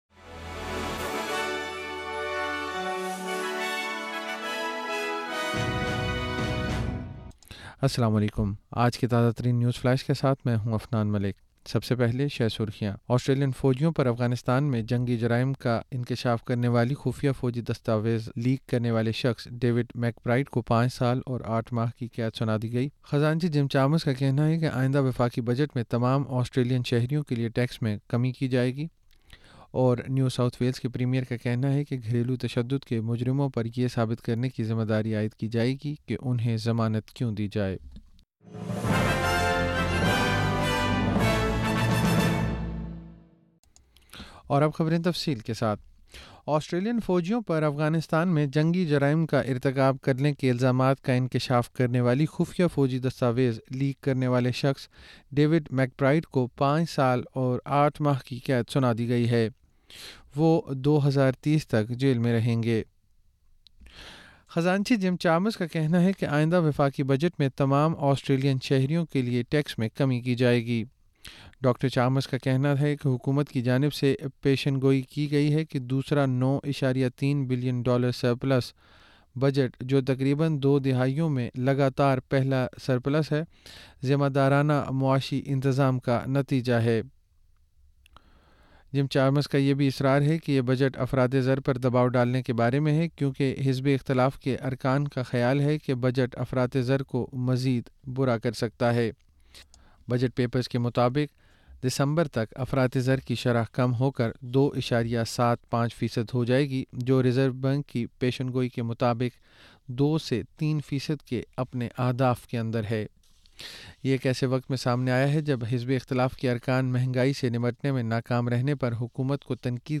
نیوز فلیش 14 مئی 2024: آسٹریلین فوجیوں کے افغانستان میں جنگی جرائم کے متعلق خفیہ فوجی دستاویزات لیک کرنے والے ڈیوڈ میک برائیڈ کو سزا